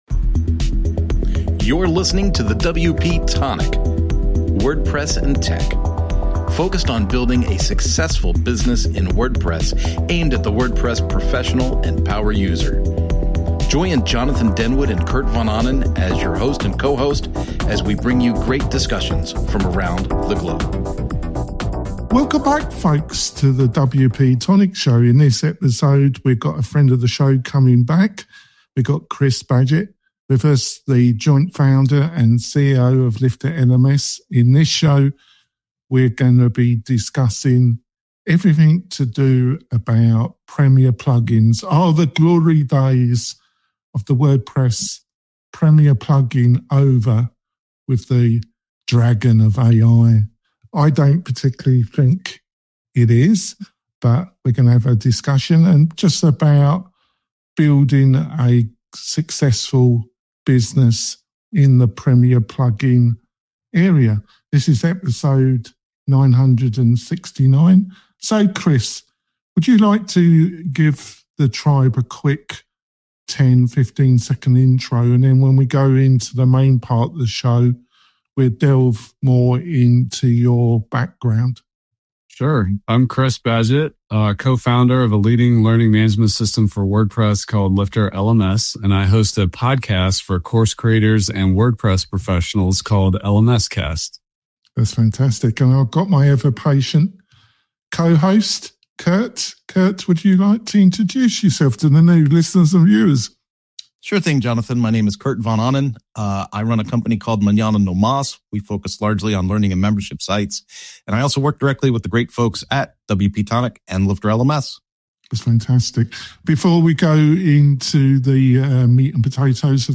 Special Guest